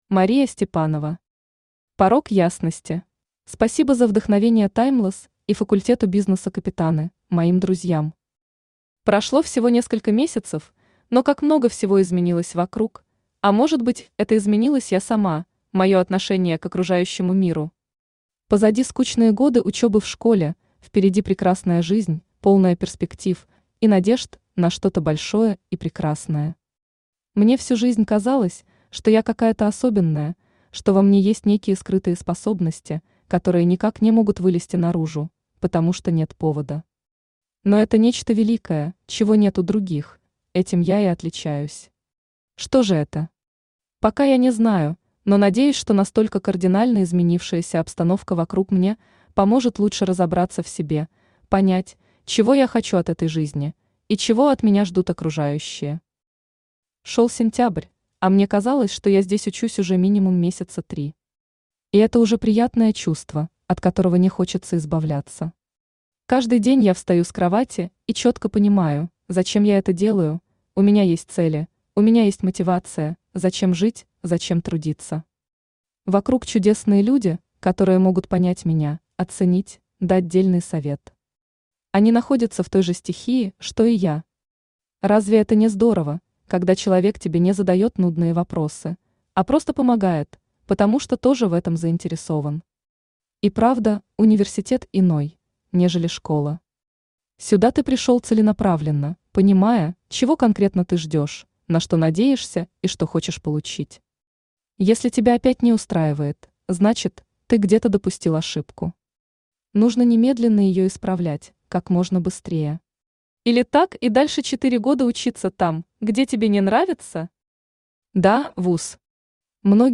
Аудиокнига Порог ясности | Библиотека аудиокниг
Aудиокнига Порог ясности Автор Мария Виктровна Степанова Читает аудиокнигу Авточтец ЛитРес.